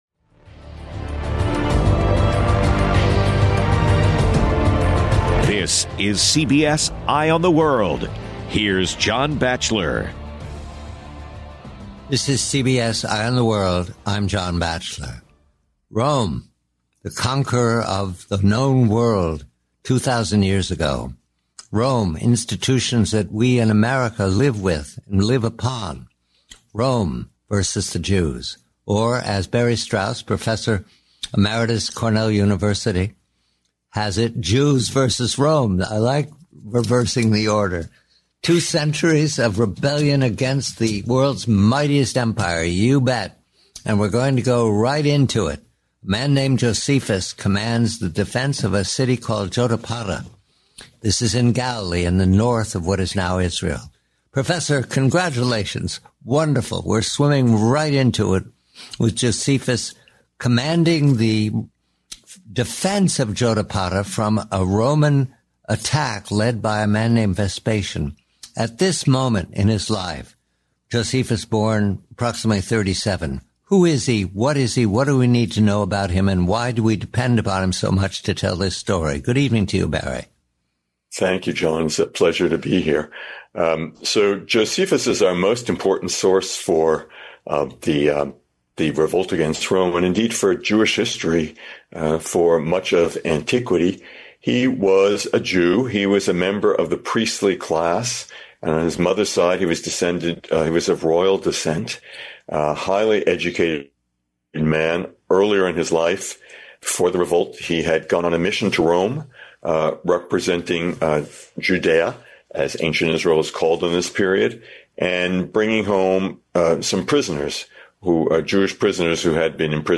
JOSEPHUS AND THE SIEGE OF JODAPATA Guest: Professor Barry Strauss The Jewish revolt against Rome, starting in 66 AD, is primarily chronicled by Josephus, a leader of the revolt and later historian. Josephus commanded the defense of Jodapata against General Vespasian. After defeat, Josephus survived a mass suicide pact, surrendered, and convinced Vespasian not to kill him by predicting he would become Roman emperor.